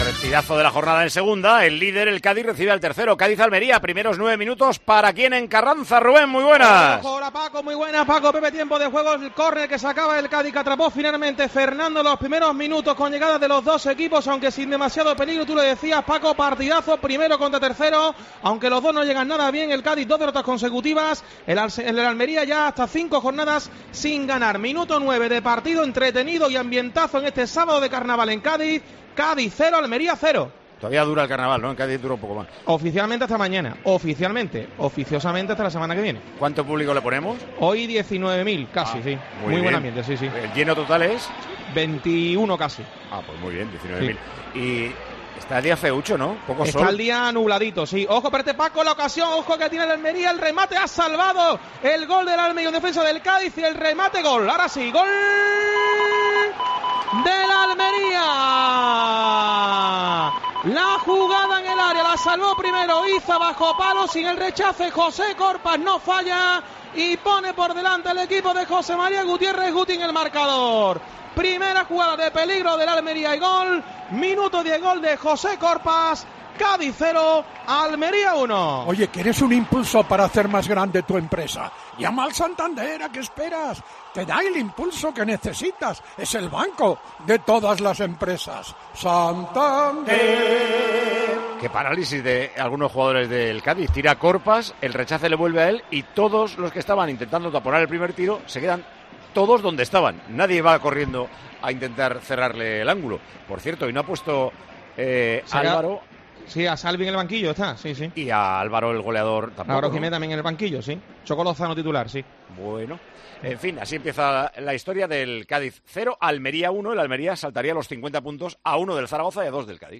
Así sonaron los goles del Cádiz 2-1 Almería en Tiempo de Juego